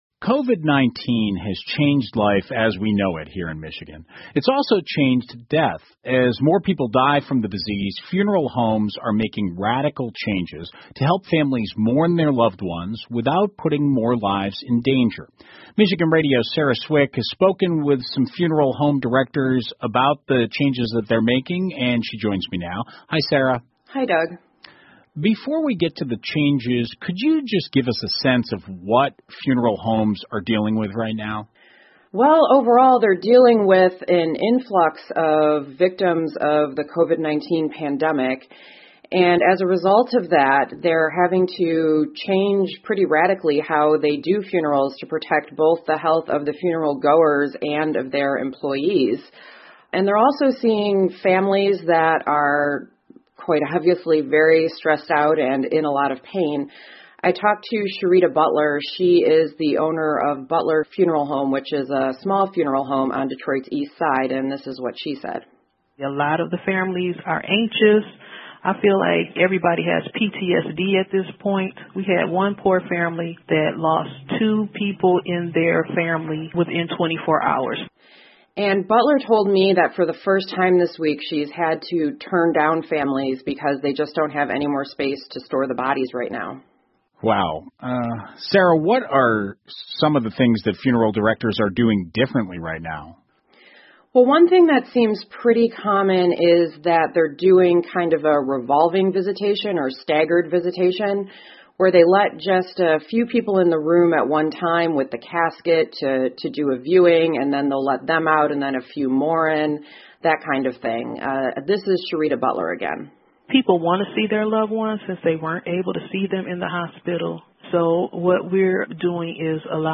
密歇根新闻广播 新型冠状病毒颠覆死亡业务 听力文件下载—在线英语听力室